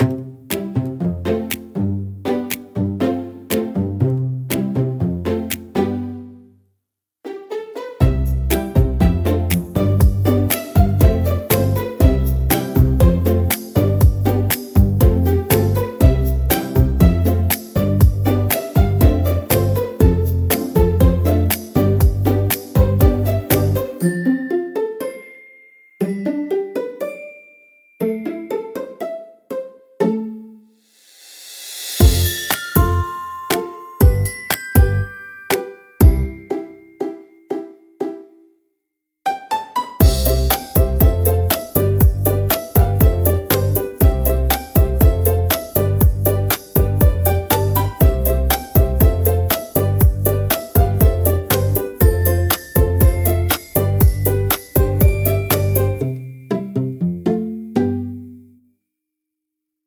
オリジナルのピチカートは、ピチカート奏法のストリングスを主体にした穏やかで可愛らしい曲調が特徴です。
軽やかな弦のはじき音が繊細に響き、優しく愛らしい雰囲気を作り出します。